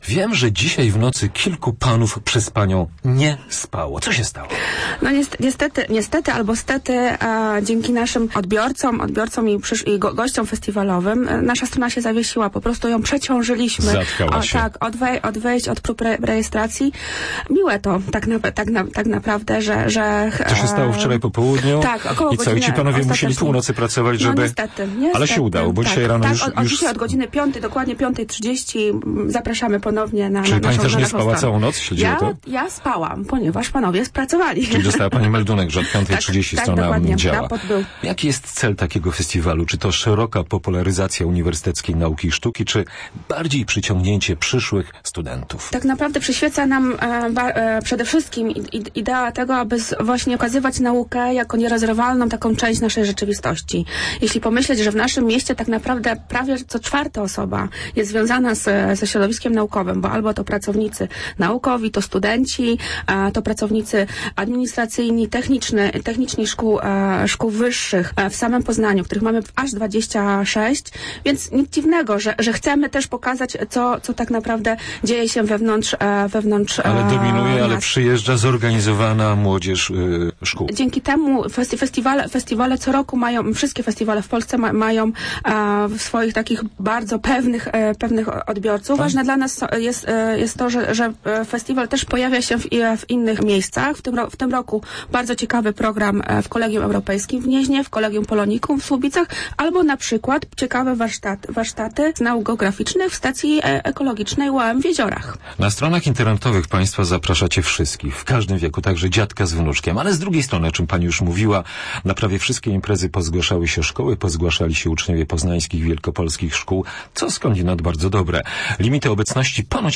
fg21jxq1s49jpfk_rozmowa_festiwal_nauki_sztuki.mp3